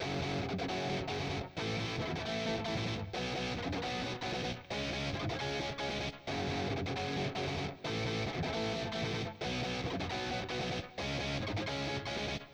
ギター
それを後押しするべくエレキギターもハイゲインな感じで入れました。
※プラグインの設定的にはクランチでした。右のDRIVEでだいぶ歪んでる気もしますが。